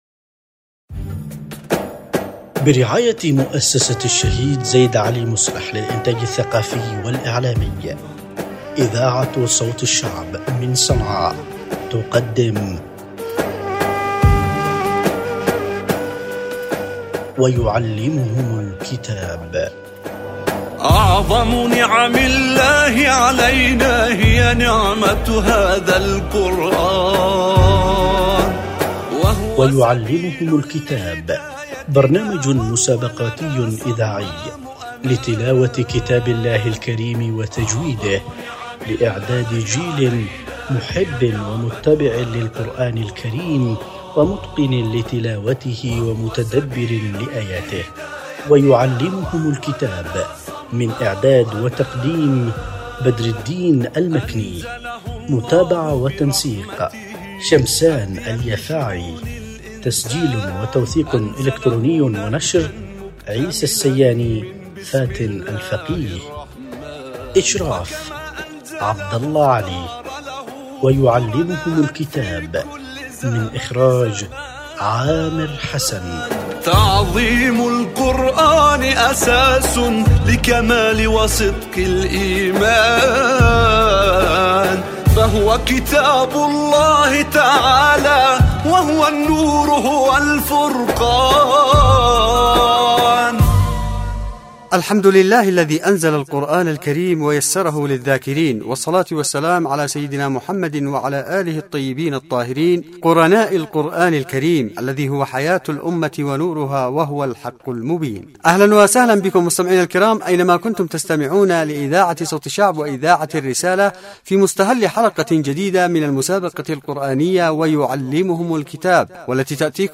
مسابقة-القرآن-الكريم-_الحلقة12.mp3